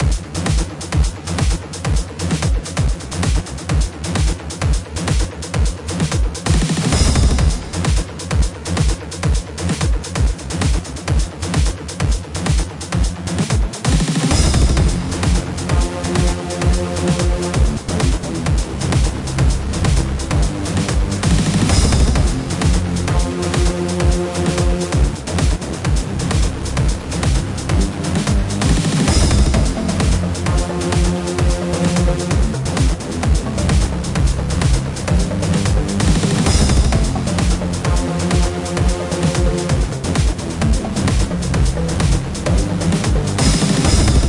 描述：一个130平方英尺的相当坚硬的舞蹈技术节拍循环，鼓和合成器。相当简单明显的进展。在FL工作室创建。
Tag: FL-工作室 音乐 电子乐 节拍 舞蹈 130-BPM 垃圾